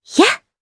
Yuria-Vox_Attack1_jp_b.wav